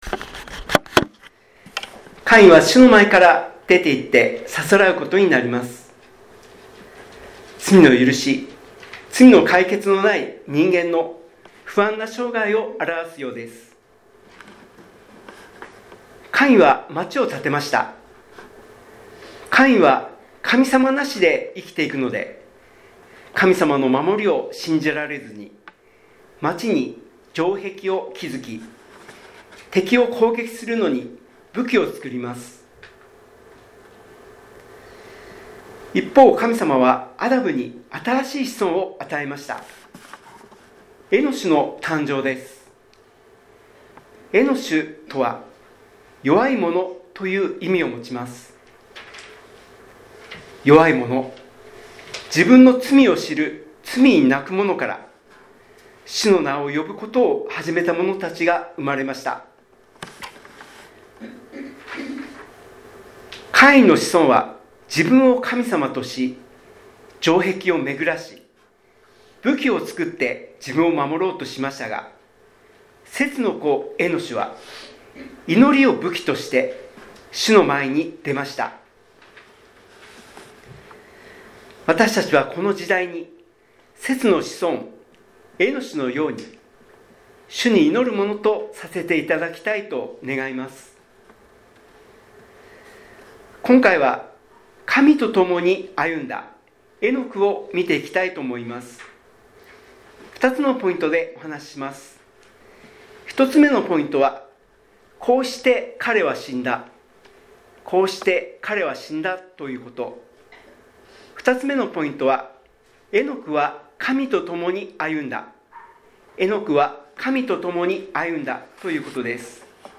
礼拝メッセージ – コイノニアキリスト教会